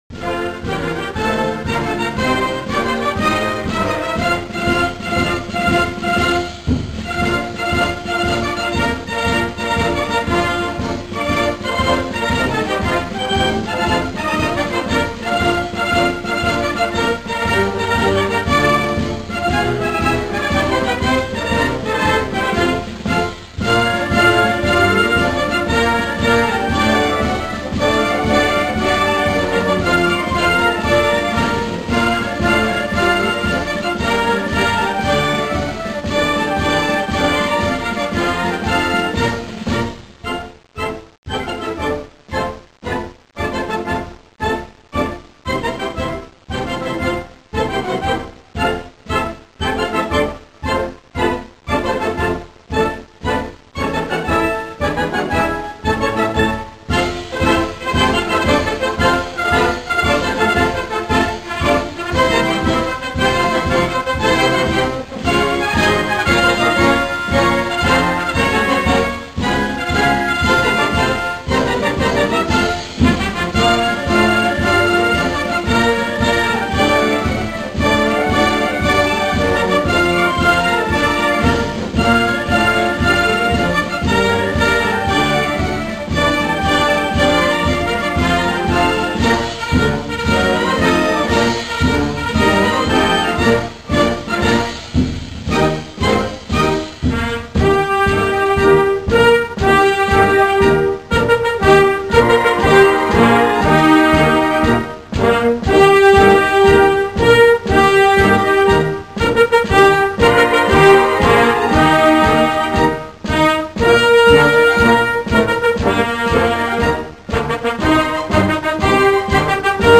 Marcia-DOrdinanza-DellAeronautica-Militare.mp3